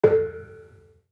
Kenong-dampend-A3-f.wav